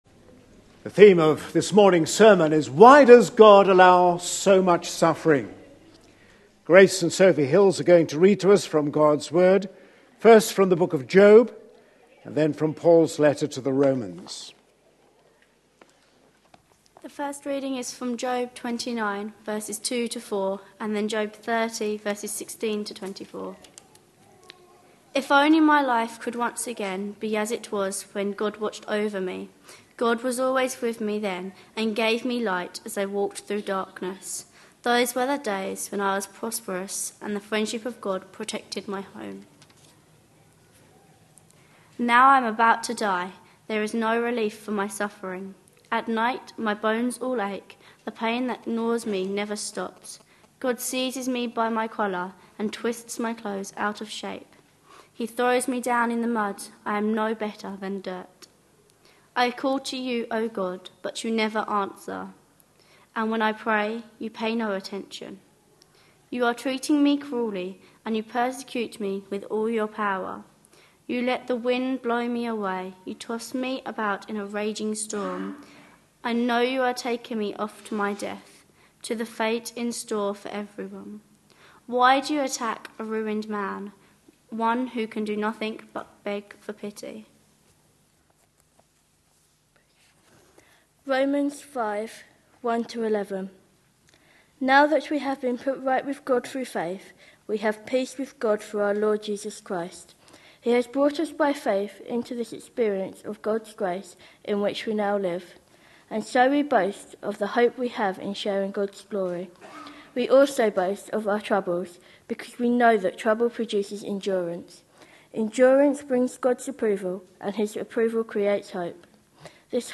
A sermon preached on 10th November, 2013, as part of our Objections to faith answered! series.